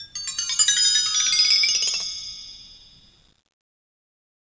BellTree_Stroke1_v1_Sum.wav